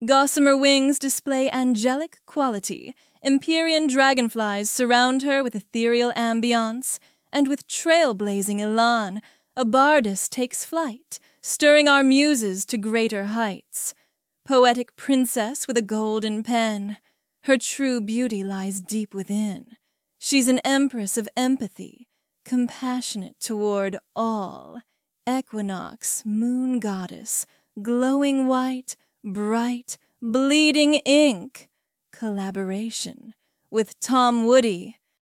The spoken must have been fun to generate? Lol it sounds so different!!!